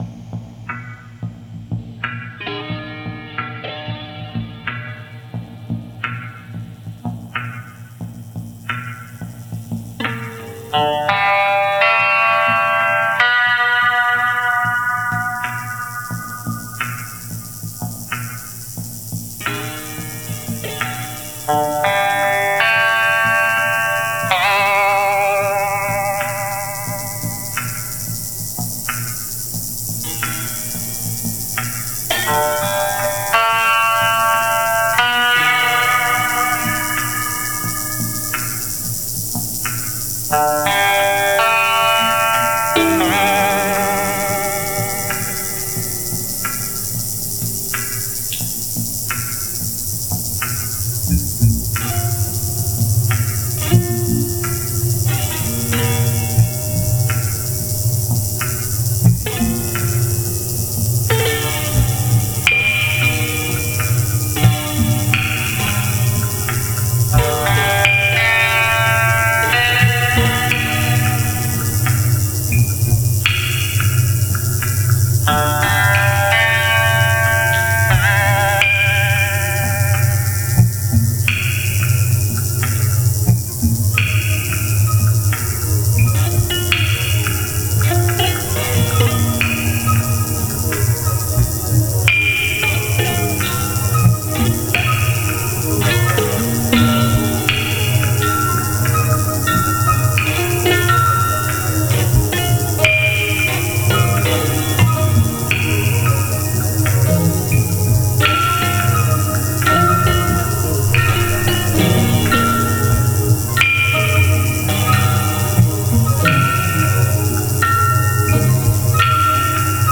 Guitares, bandes préparées, objets
Synthétiseurs analogiques .
Guitare préparée, voix .